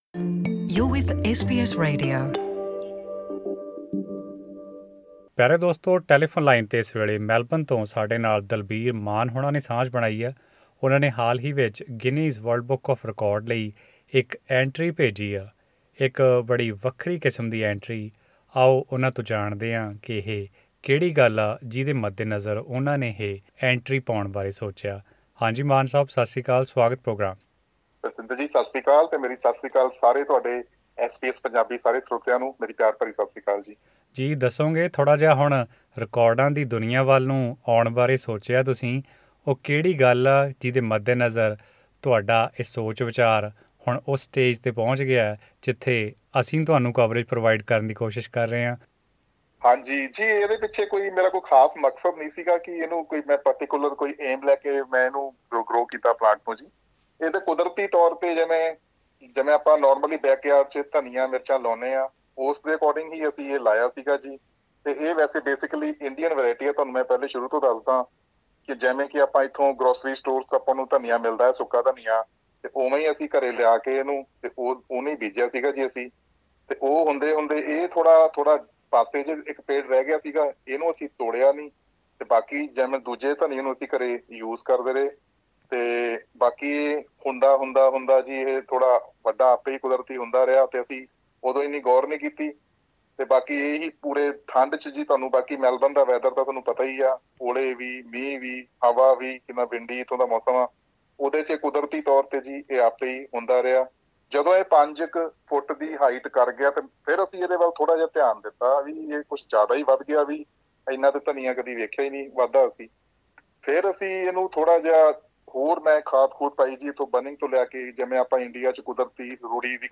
ਐਸ ਬੀ ਐਸ ਪੰਜਾਬੀ ਨਾਲ ਇੱਕ ਇੰਟਰਵਿਊ